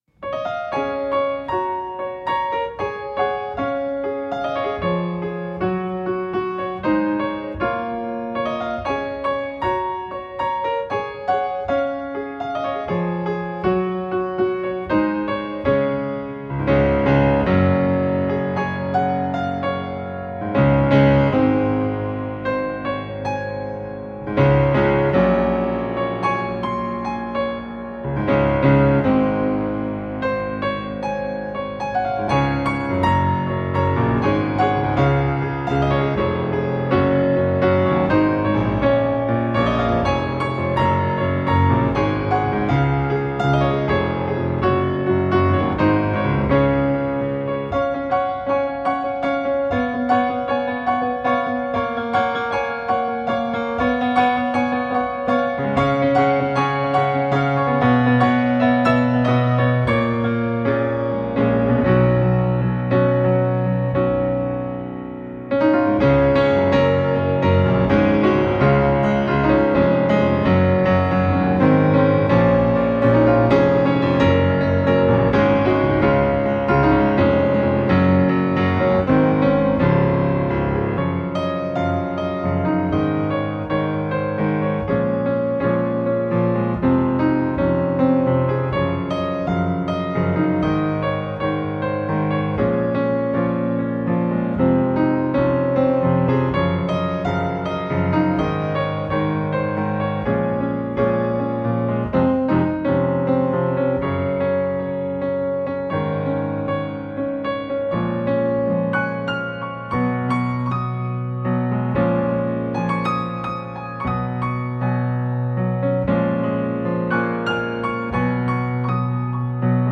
Soft and relaxing ambient piano to soothe your mind.